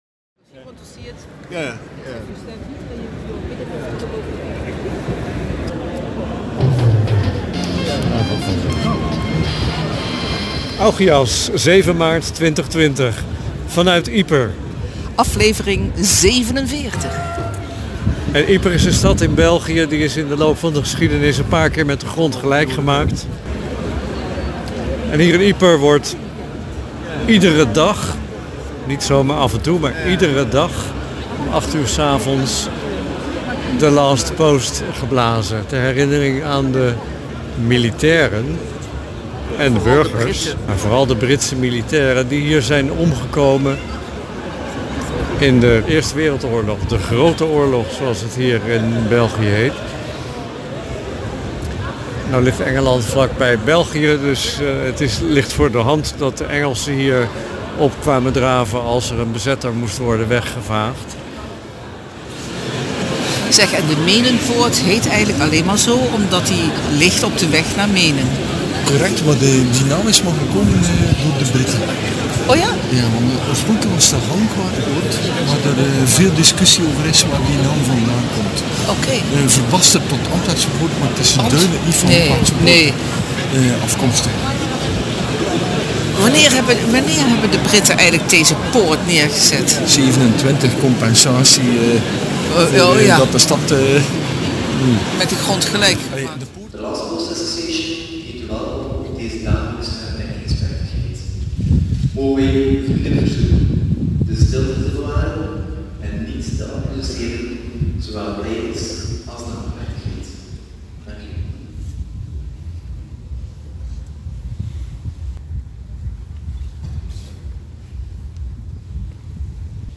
In zes minuten hoor je de eenvoudige maar indrukwekkende plechtigheid in de Menenpoort aan de stadswal van Ieper. Helaas woei er een venijnige koude wind door de Menenstraat; maar vergeleken bij het leed van de bevolking en de militairen in 1917 was dat slechts een klein ongemak.